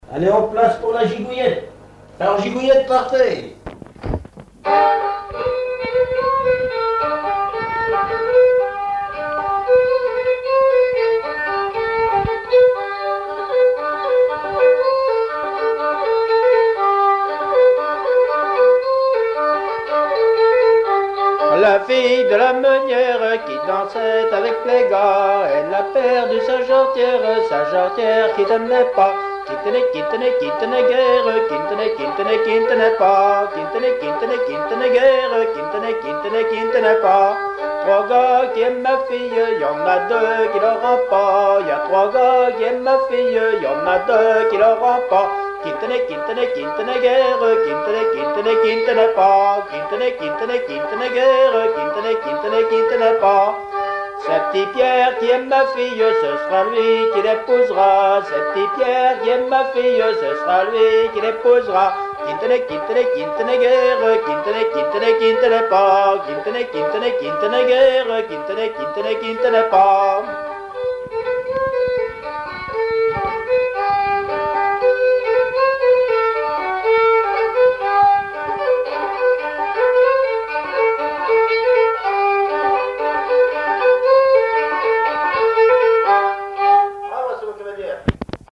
Chants brefs - A danser
danse : gigouillette
Auto-enregistrement
Pièce musicale inédite